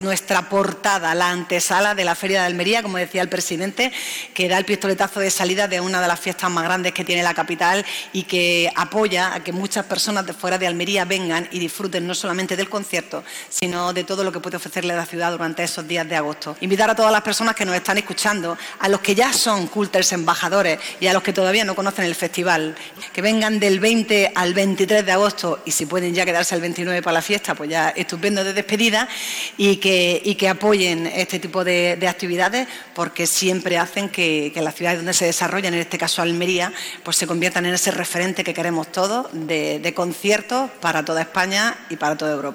07-04_cooltural_alcaldesa.mp3.mp3